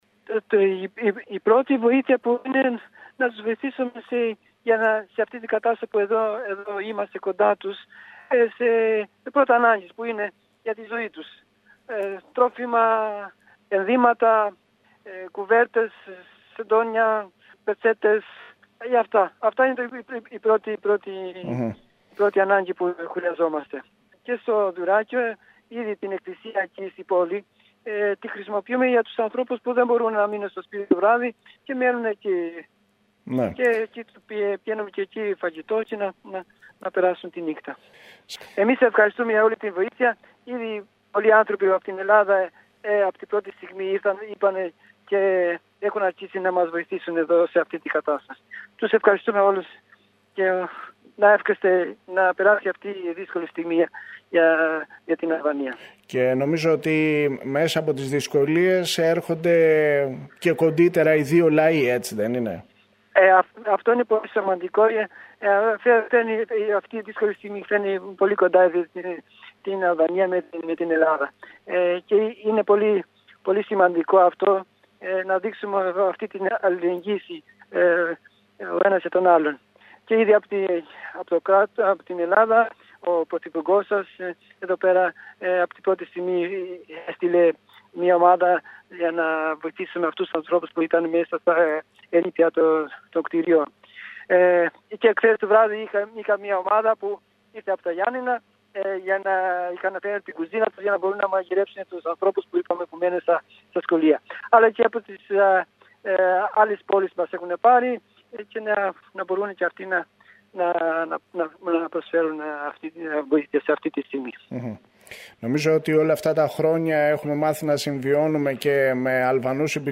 Σήμερα το πρωί μιλήσαμε με τον Μητροπολίτη Απολλωνίας Νικόλαο οποίος μίλησε για την κατάσταση στην περιοχή του Δυρραχίου και τόνισε ότι από την πρώτη στιγμή η Ελλάδα στάθηκε στο πλευρό των πληγέντων.